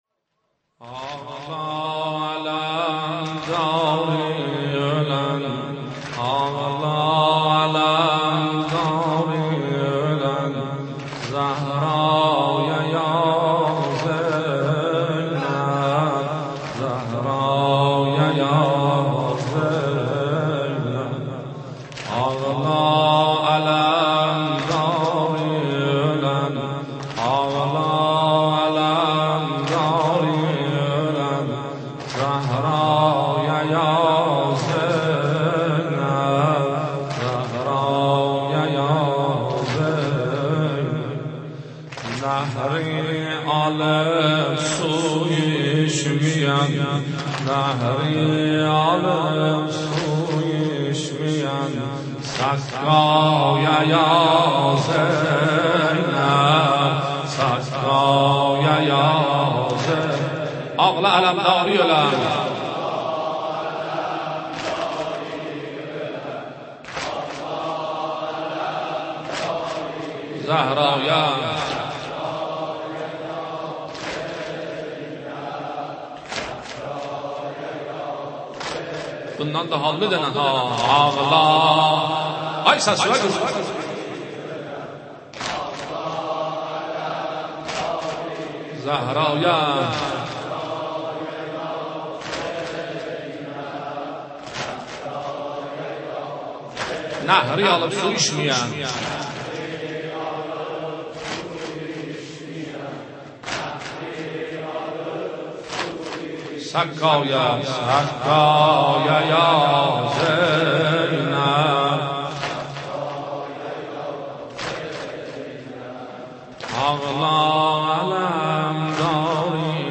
محرم96 - نوحه -آغلا علمداری اولن زهرایه یا زینب